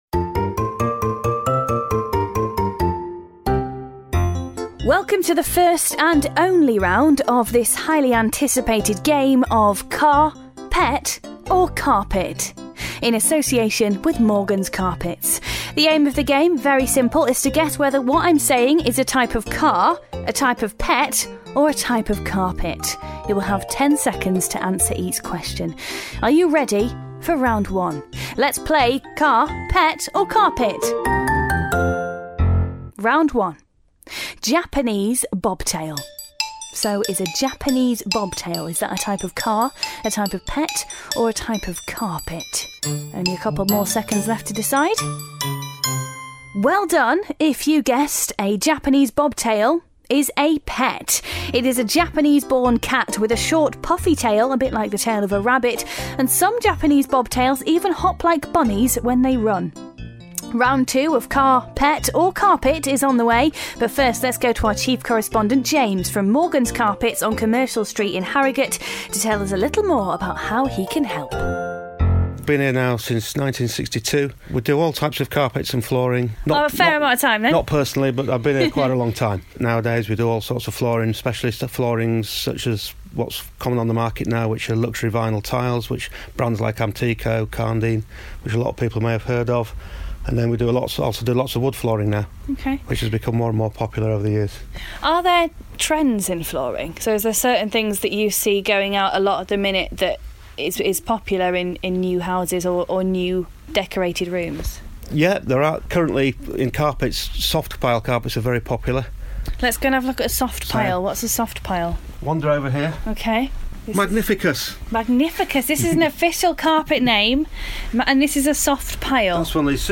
A radio exclusive tonight… a slightly unusual take on a radio game show…